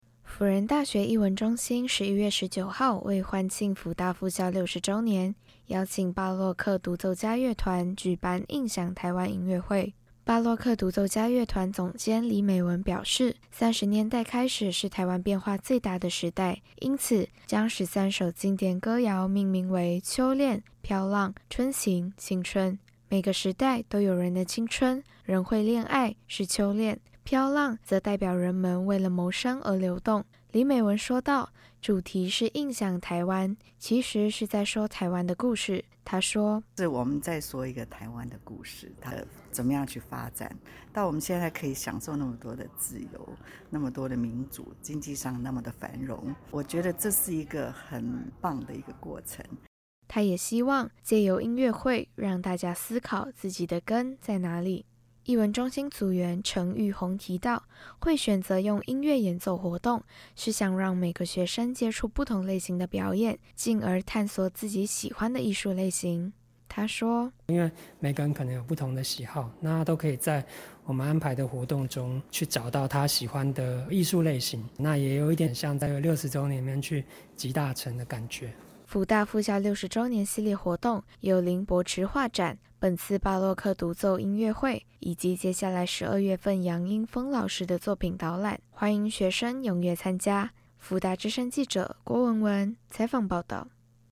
輔仁大學藝文中心十一月十九號為歡慶輔大復校60周年，舉辦「印象•台灣」音樂會。邀請巴洛克獨奏家樂團，演奏出臺灣印象中內斂精緻的創新與傳承。